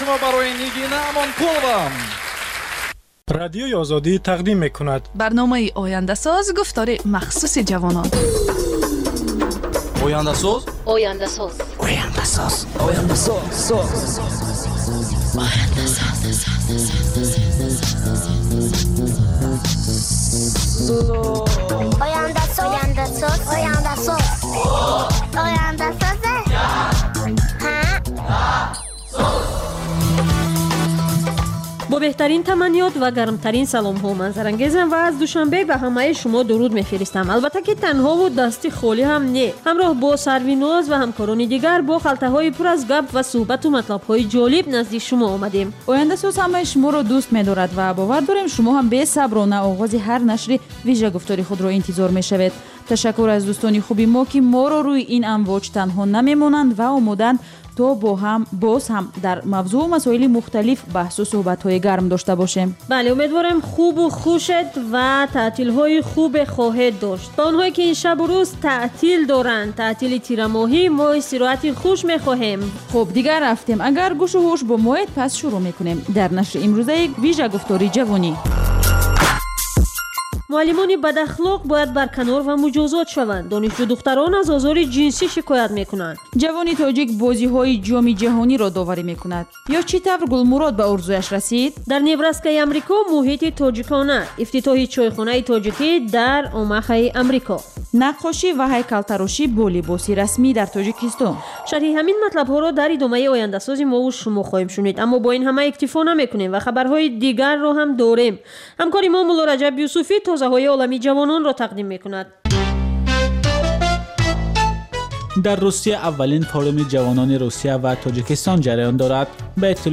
"Ояндасоз" барномаи вижаи ҷавонон, ки муҳимтарин масоили сиёсӣ, иқтисодӣ, иҷтимоӣ ва фарҳангии Тоҷикистону ҷаҳонро аз дидгоҳи худи онҳо ва коршиносон таҳлил ва баррасӣ мекунад. Бар илова, дар ин гуфтор таронаҳои ҷаззоб ва мусоҳибаҳои ҳунармандон тақдим мешавад.